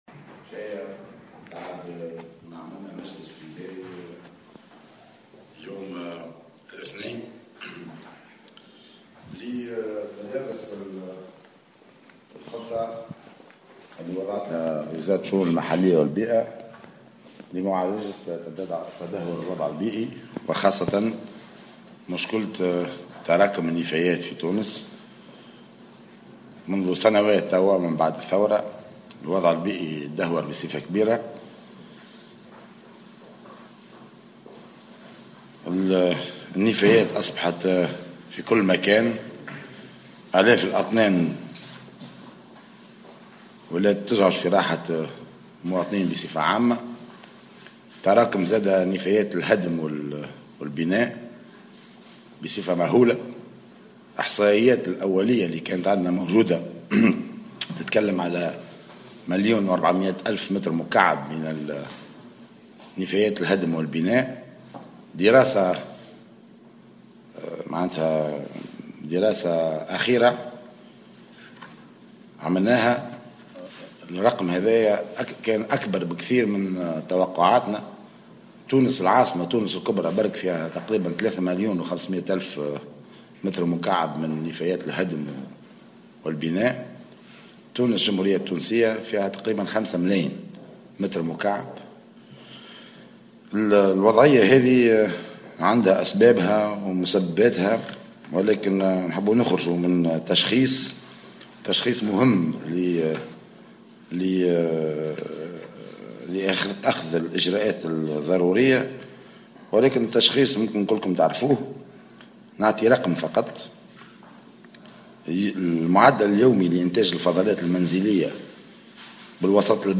وأفاد المؤخر خلال ندوة صحفية اليوم الأربعاء بقصر الحكومة بالقصبة ان المعدل اليومي لانتاج النفايات المنزلية بالوسط البلدي يبلغ 10600 طنا، ترفع البلديات منها ما معدله 9000 آلاف طن أي بنسبة 85% ما يعني تراكم 1600 طن يوميا من النفايات المنزلية التي لا يتم رفعها من الطريق العام، علما وأن بعض البلديات لا تتمكن من رفع سوى 25 % من هذه الفضلات.